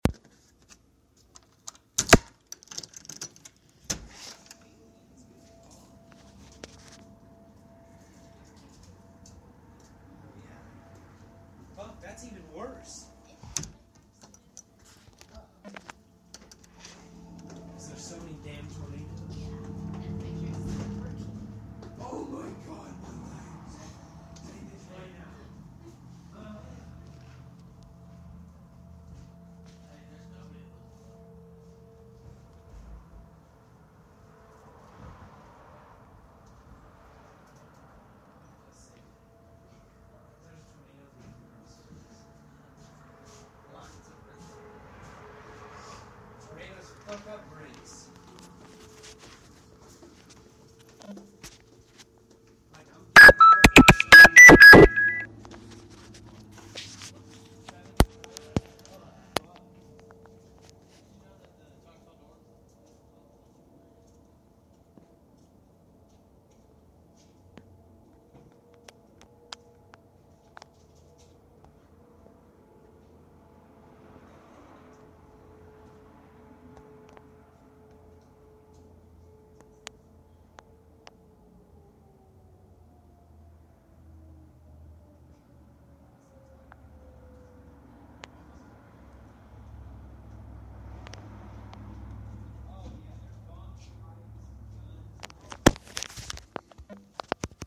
tornado sirens
sry for the loud bweeps